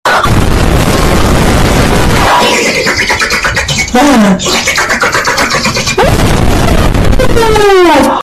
Funny DRINKING
funny-drinking.mp3